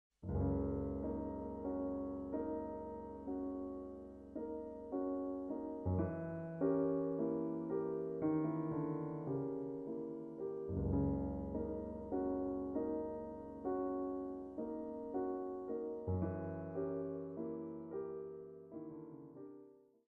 Instrument: piano solo.